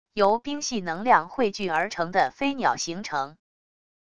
由冰系能量汇聚而成的飞鸟形成wav音频